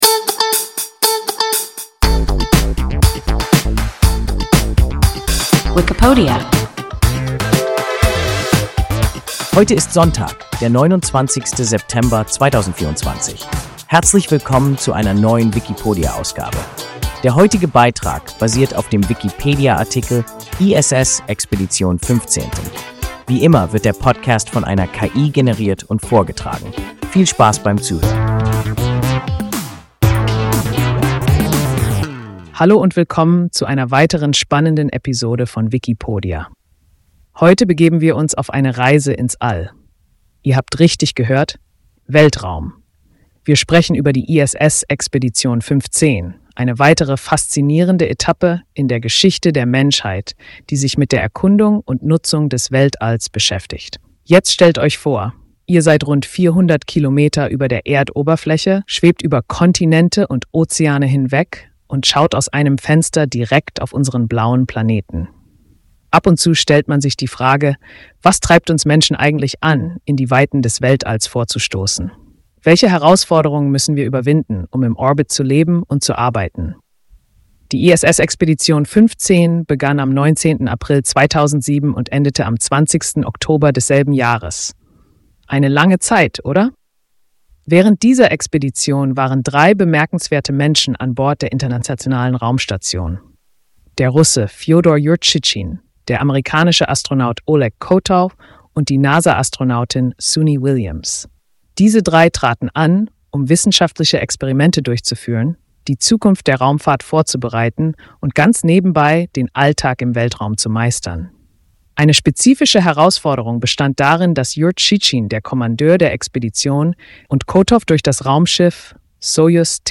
ISS-Expedition 15 – WIKIPODIA – ein KI Podcast